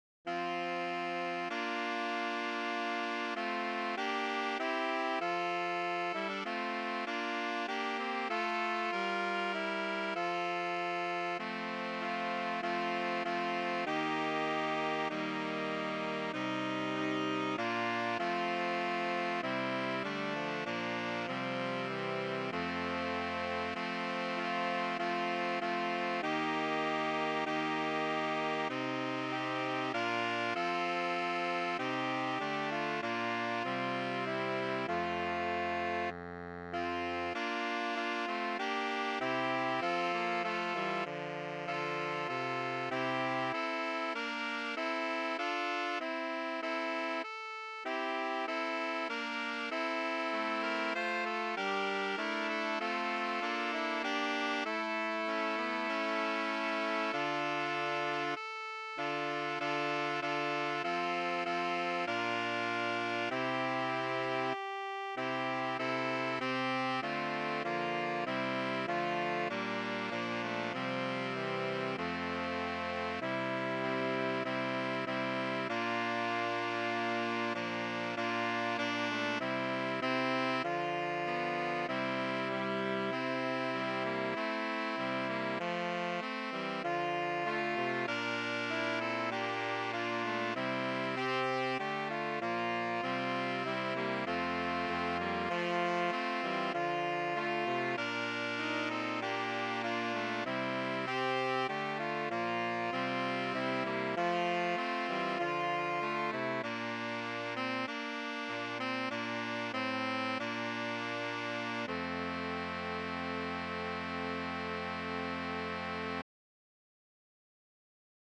SAXOPHONE QUARTET
FOR 2 ALTO SAXOPHONES. TENOR SAX, BARI SAX